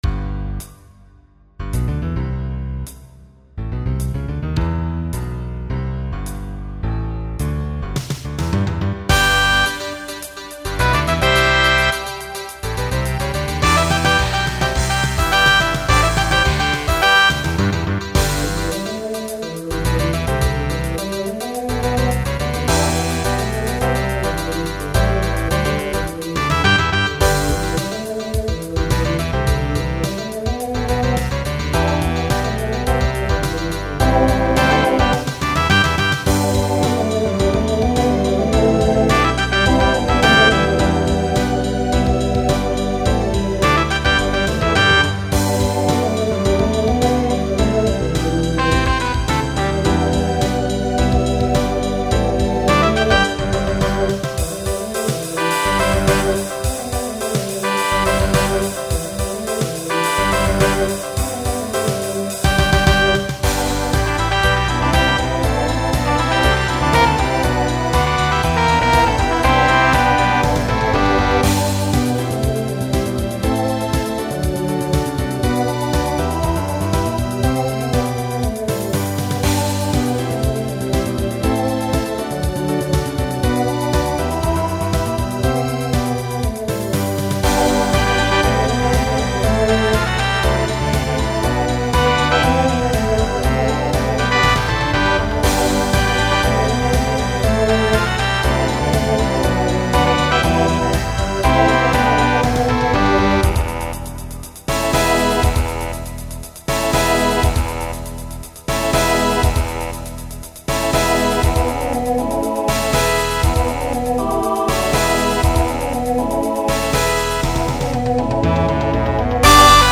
Voicing Mixed
Genre Pop/Dance